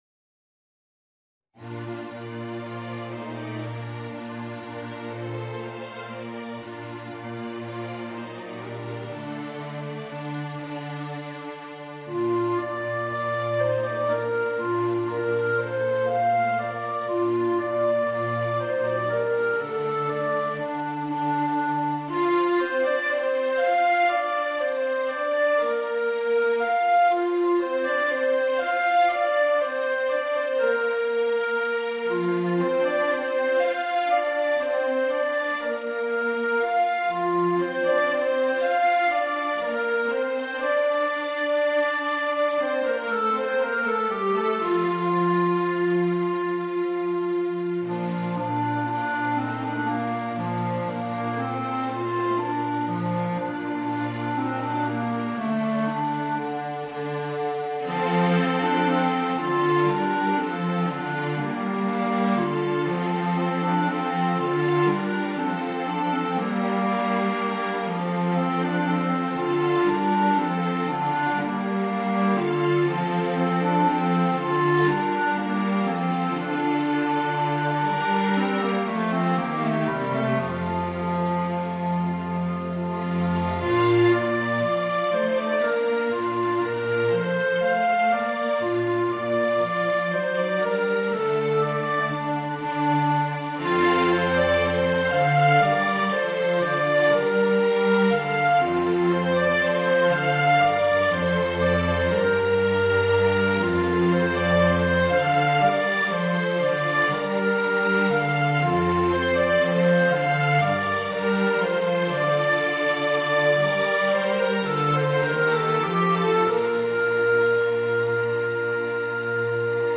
Voicing: Tenor Saxophone and String Quartet